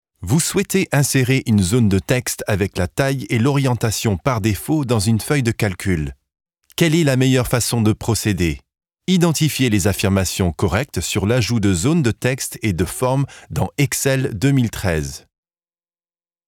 bilingual French and English voice-over actor with a medium, smooth voice that suits 25-35 yo professional, educated male. He is at ease in both languages without a trace of an accent.
Sprechprobe: eLearning (Muttersprache):